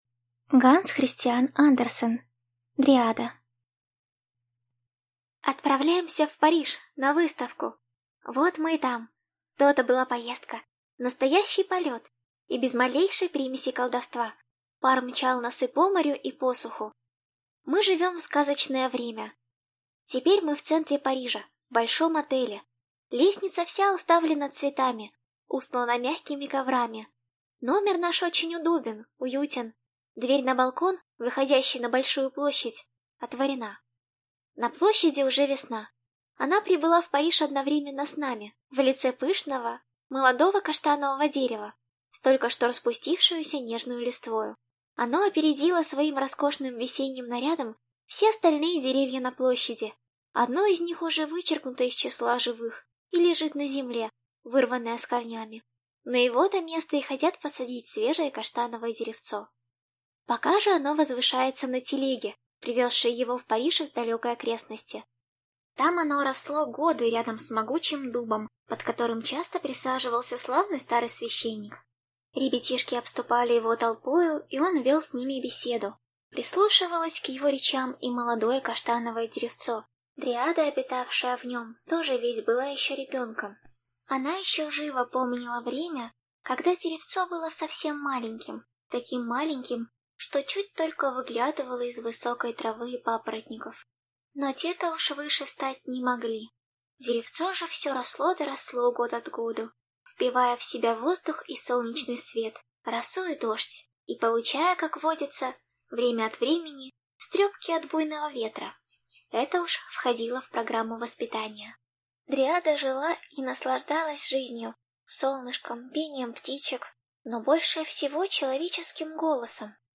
Аудиокнига Дриада | Библиотека аудиокниг